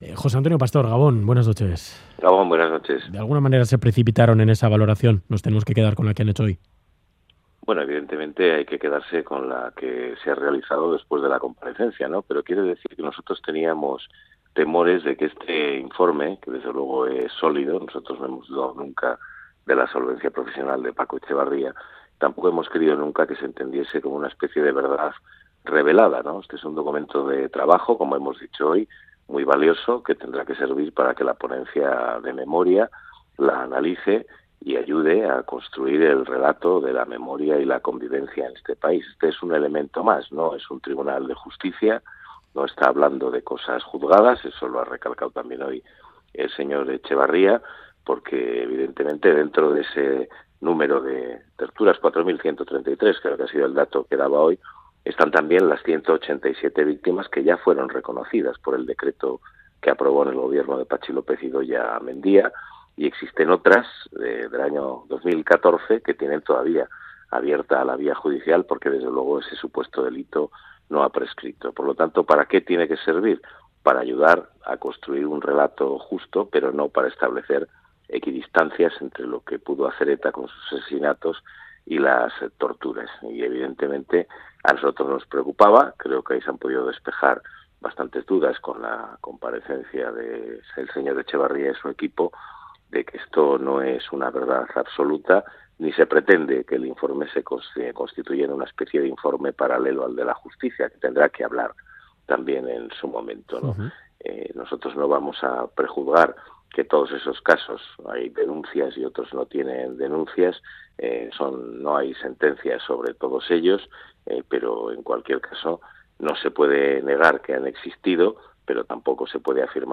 Radio Euskadi GANBARA 'Teníamos temores de que el informe se entendiese como una verdad revelada' Última actualización: 19/03/2018 22:55 (UTC+1) Entrevista en 'Ganbara' de Radio Euskadi al portavoz del grupo parlamentario socialista en el Parlamento Vasco, José Antonio Pastor.